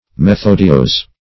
Methodios \Me*thod"ios\, n. The art and principles of method.